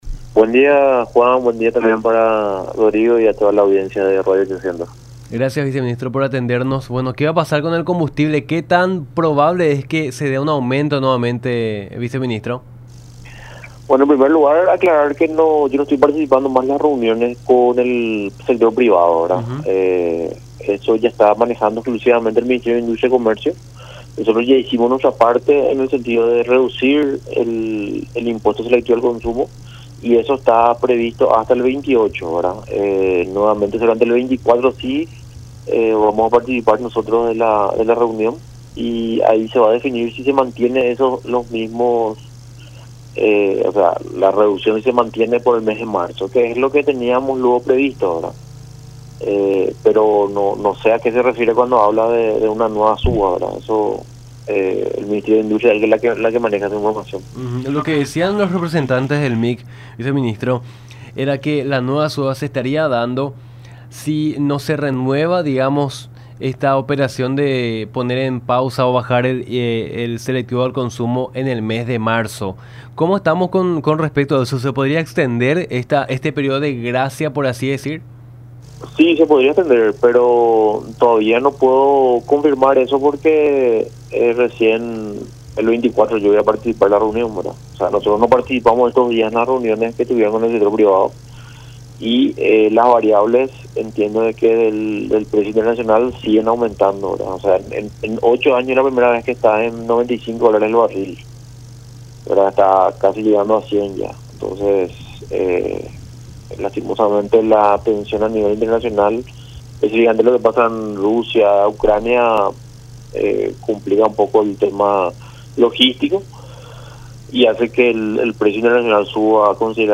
El jueves 24 (de febrero) voy a participar de la reunión con los del sector privado”, dijo Orué en diálogo con Nuestra Mañana por La Unión, mencionando que las reducciones de porcentajes de tributos dispuestas en la última ocasión “ya representan el tope y no se puede bajar”.